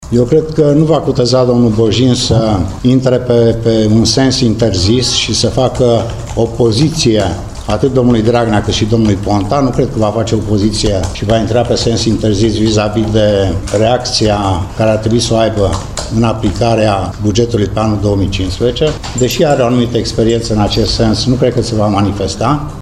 Nemulţumit de situaţia creată, consilierul judeţean liberal Marius Martinescu spune că nu îl vede pre preşedintele Titu Bjin făcând opoziţie primului ministru.